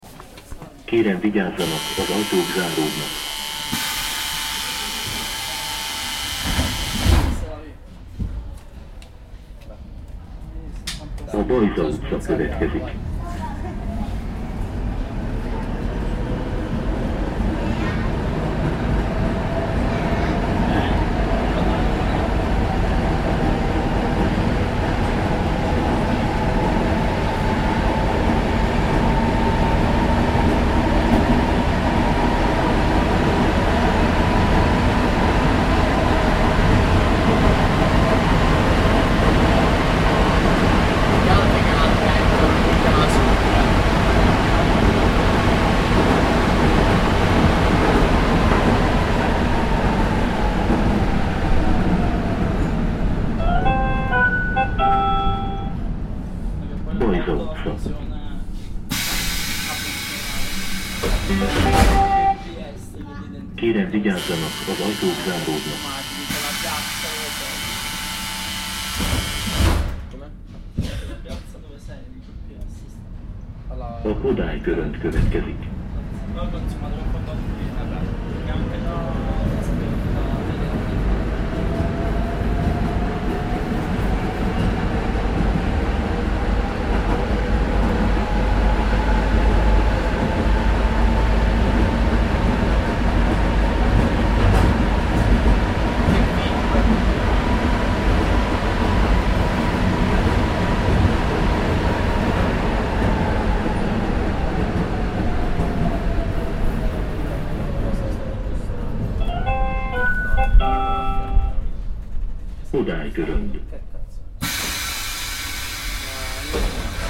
This is a short trip between Oktogon and Kodály körönd stations in Budapest by tram in September 2012.